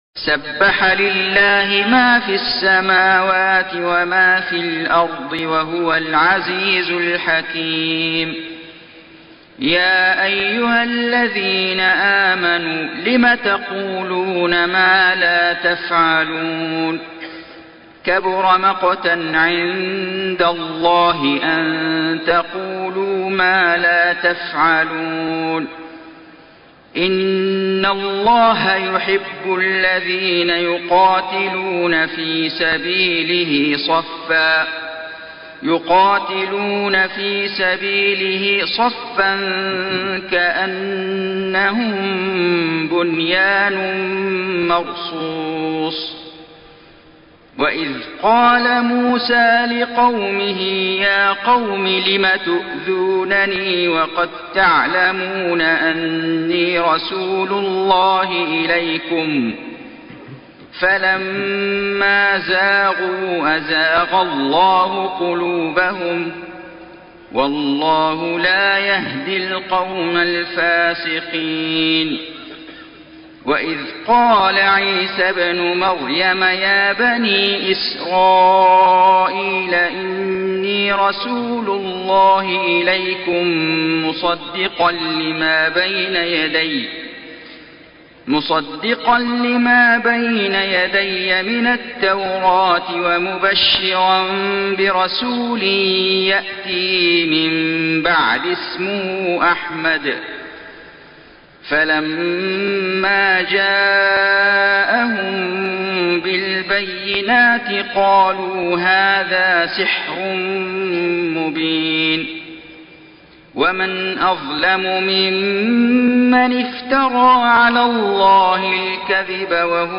سورة الصف > السور المكتملة للشيخ فيصل غزاوي من الحرم المكي 🕋 > السور المكتملة 🕋 > المزيد - تلاوات الحرمين